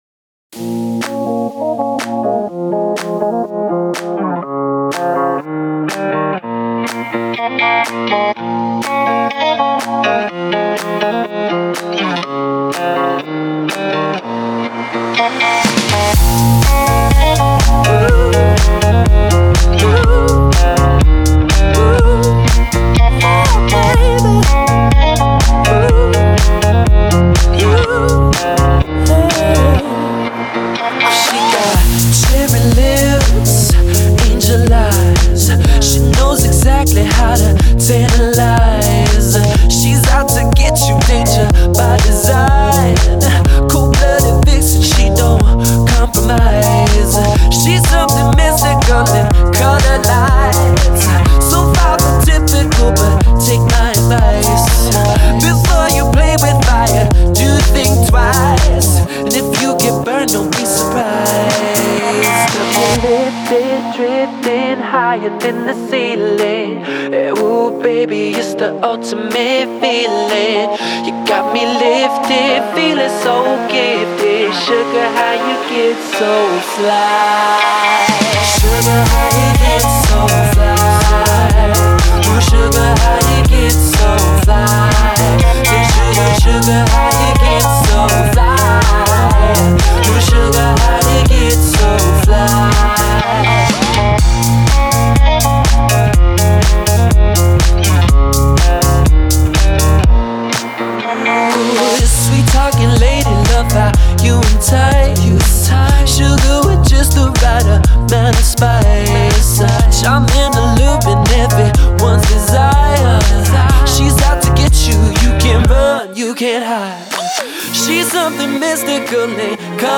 Назад в (pop)...